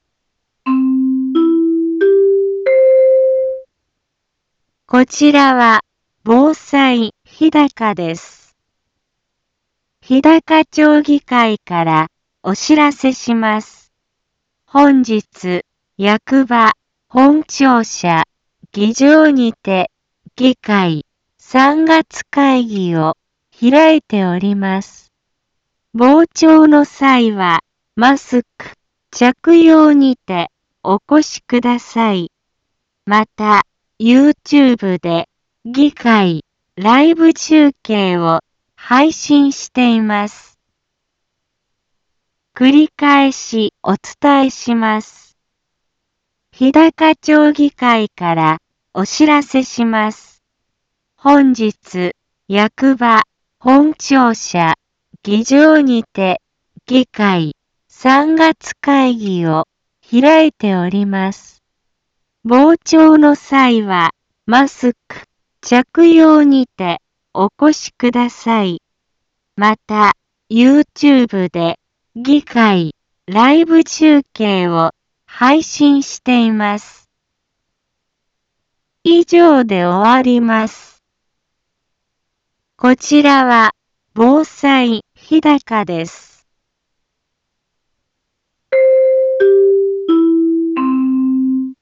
一般放送情報
BO-SAI navi Back Home 一般放送情報 音声放送 再生 一般放送情報 登録日時：2023-03-08 10:03:16 タイトル：日高町議会３月会議のお知らせ インフォメーション：こちらは防災日高です。